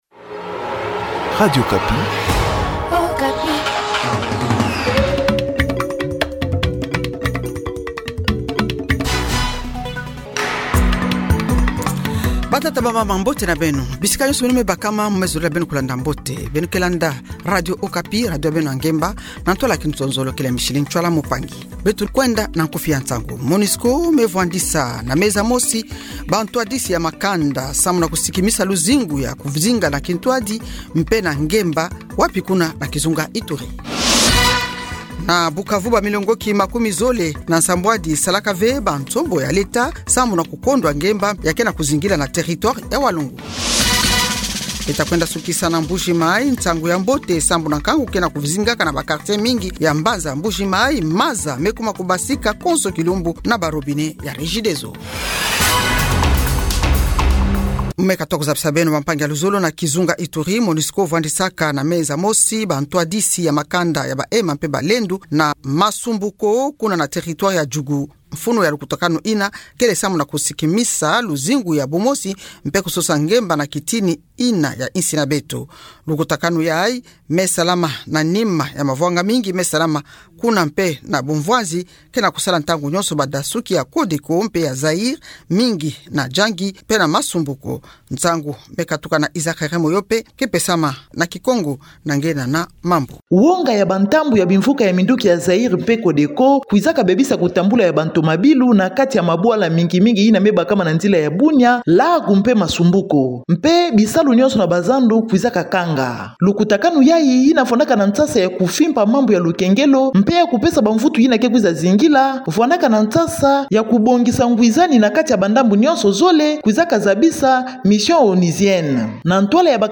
30725-p-k-p-d-journalkikongomatin-.mp3